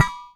metal_small_impact_shake_02.wav